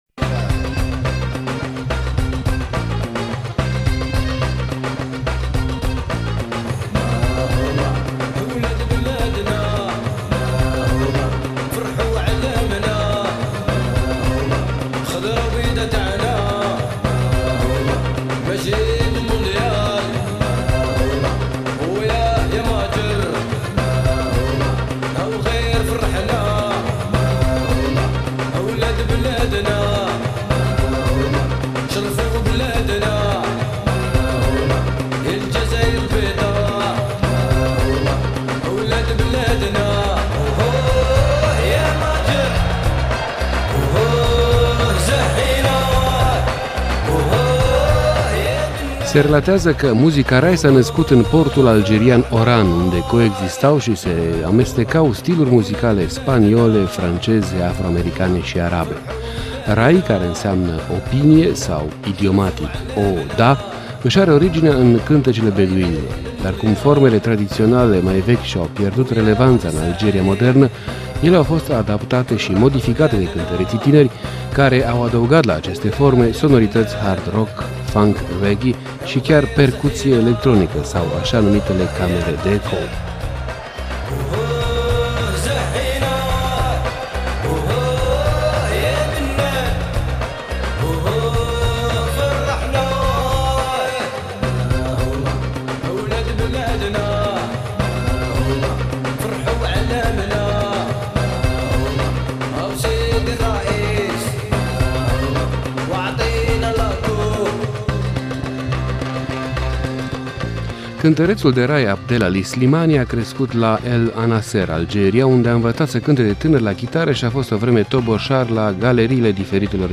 O fuziune dintre rădăcinile algeriene ale muzicii rai şi influenţele altor stiluri.